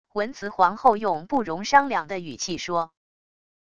文慈皇后用不容商量的语气说wav音频